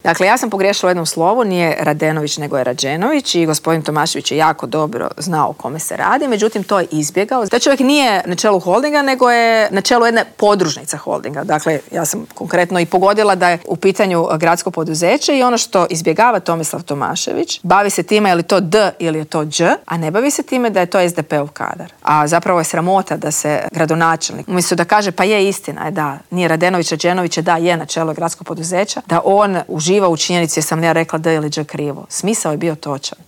U specijalnom izbornom Intervjuu tjedna Media servisa ugostili smo nezavisnu kandidatkinju za gradonačelnicu Grada Zagreba Mariju Selak Raspudić s kojom smo razgovarali o problemima Zagrepčana.